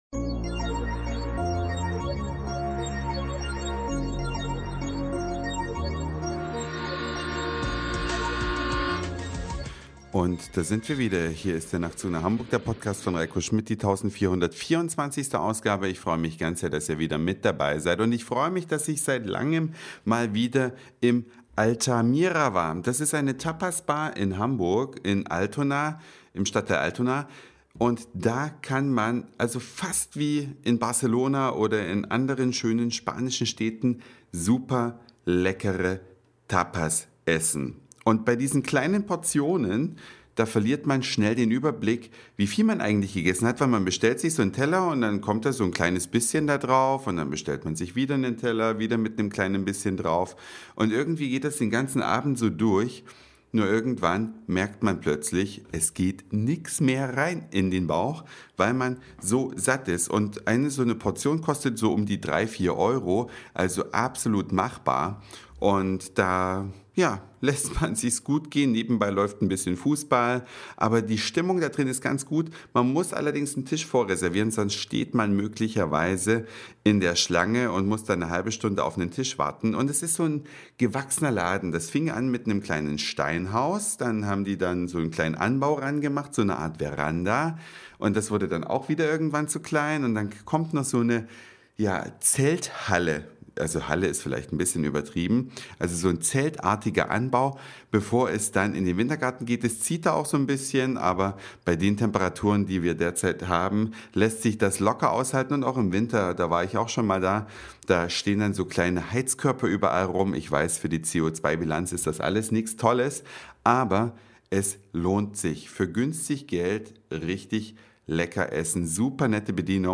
Eine Reise durch die Vielfalt aus Satire, Informationen, Soundseeing und Audioblog.
Spanische Tapas in einer urigen Bar.